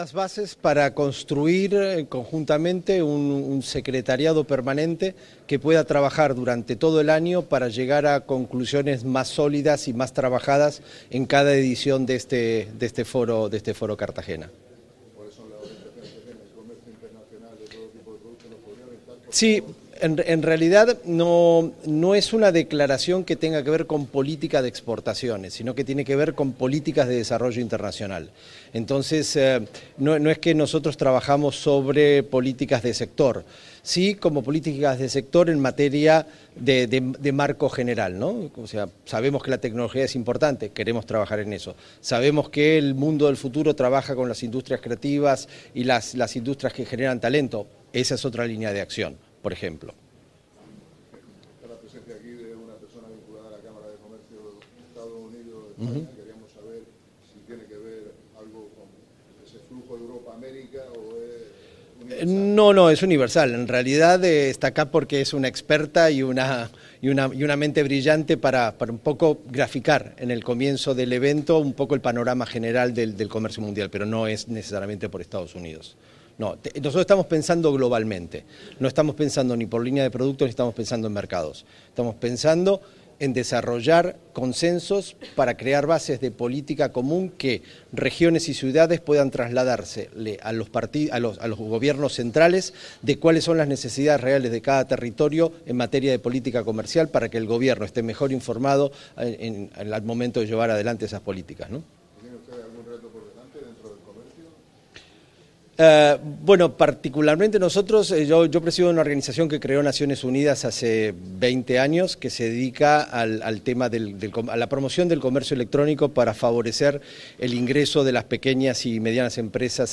Enlace a 2022-12-01 Declaraciones de Valle Miguélez